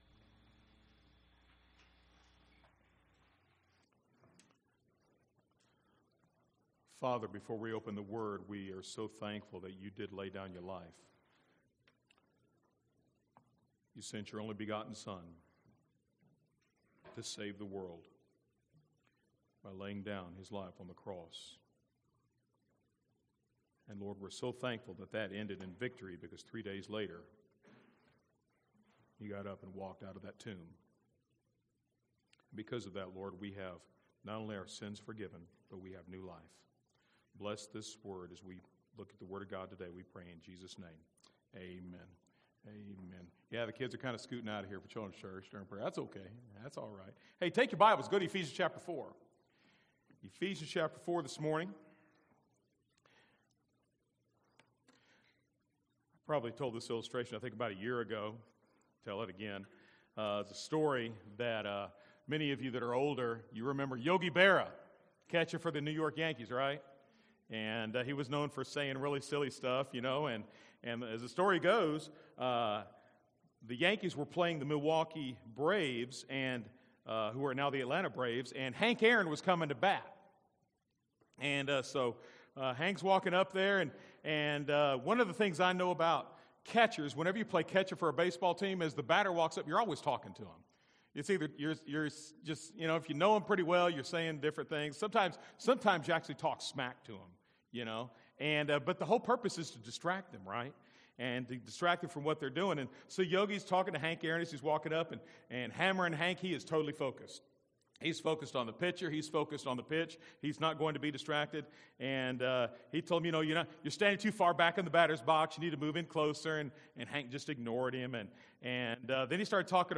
A message from the series